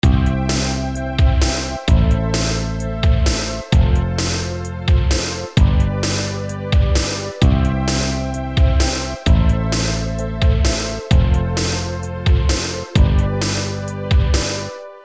まず、ドラム音源のFPCを立ち上げて、ロックなパターンを選択する。
次にベース音源のBoobassを立ち上げてコード進行にそったルートを白玉で配置。
↓出来たサンプル的な音源がこれ　（鍵盤は一切使ってないです。）